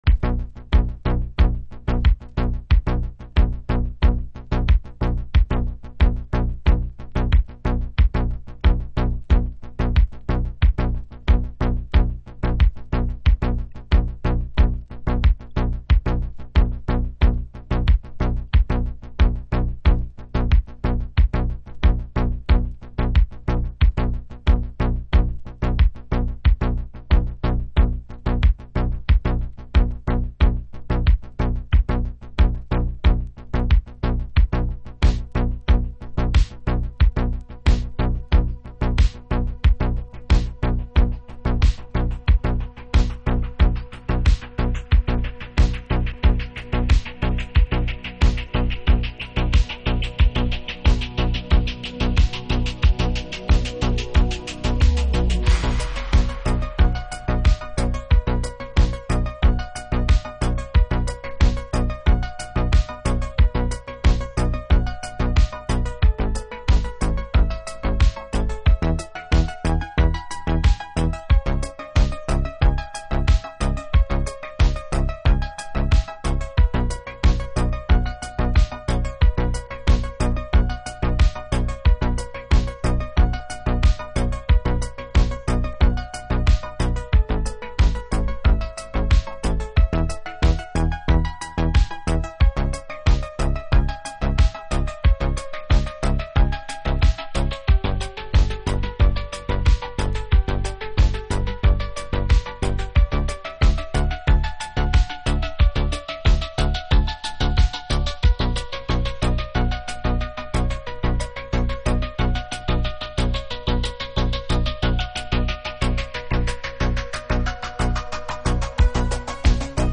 Alt Disco / Boogie